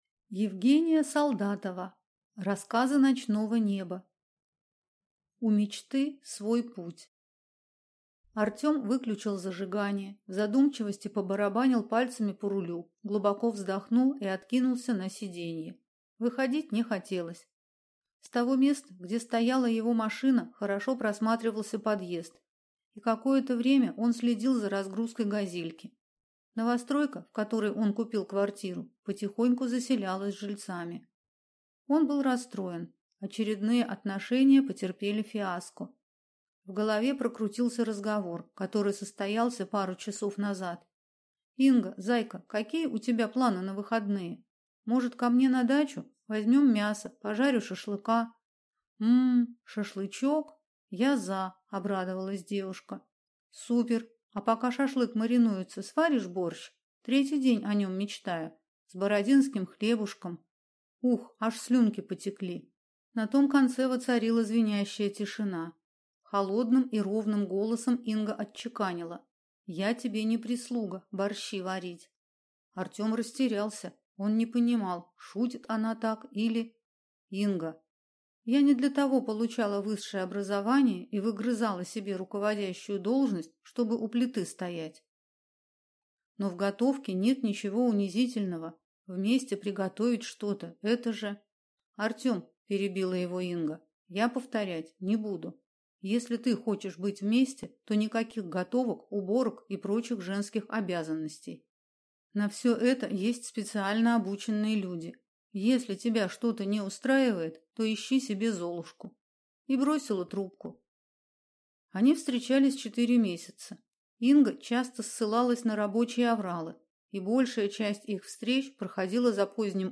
Аудиокнига Рассказы ночного неба | Библиотека аудиокниг